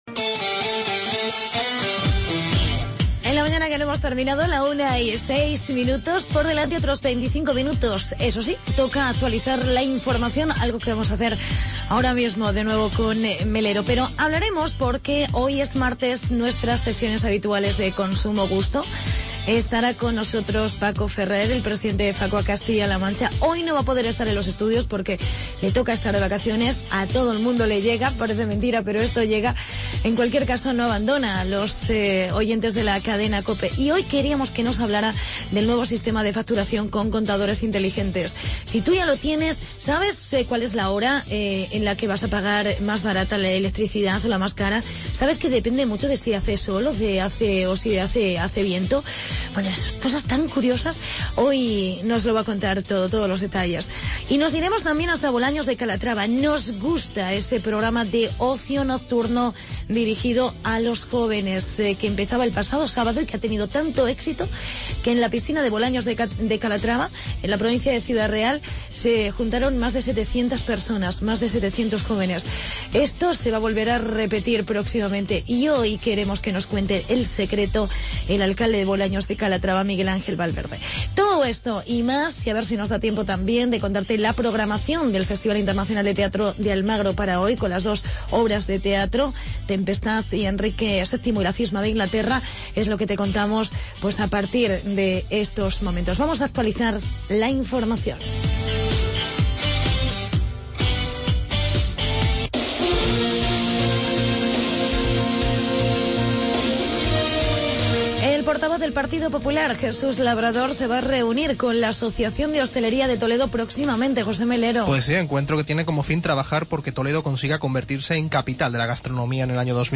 entrevista con el alcalde de Bolaños, Miguel Angel Valverde.